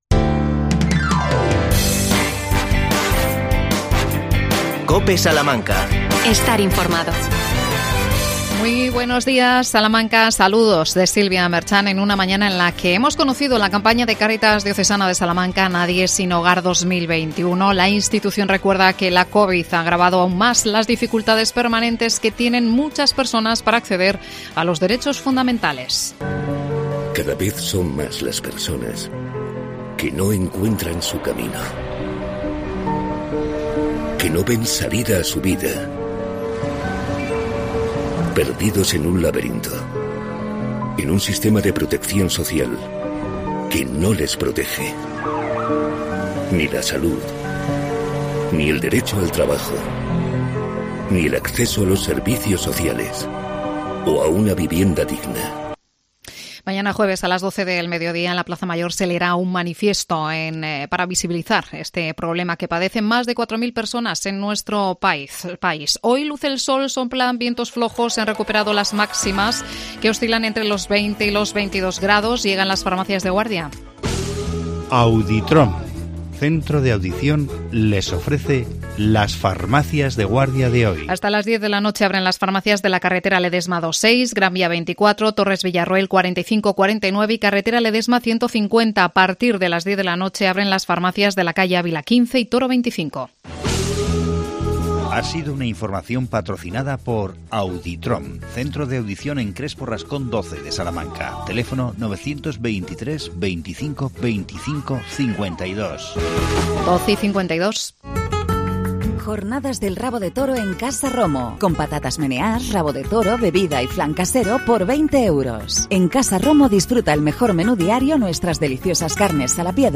AUDIO: Entrevista al Delegado Territorial de la Junta Eloy Ruiz.Temas: vacunación de la gripe y coronavirus.